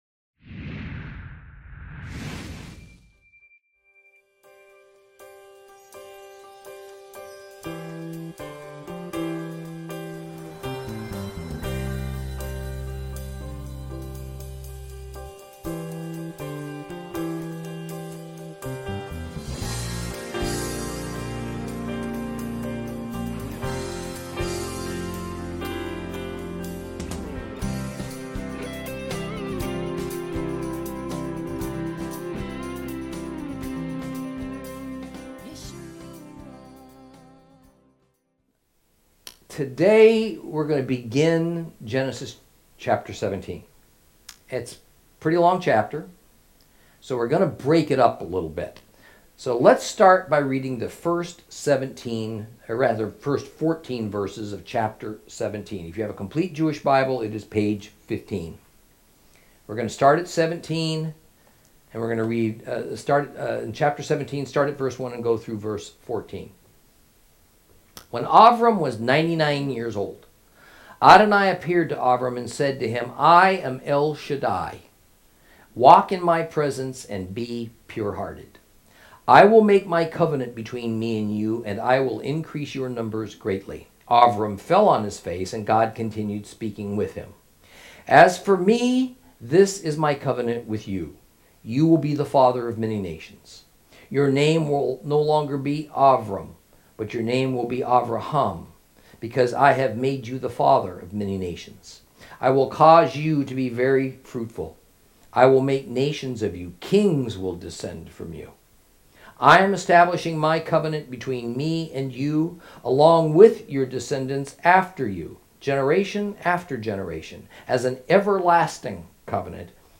Lesson 17 Ch17 Ch18 - Torah Class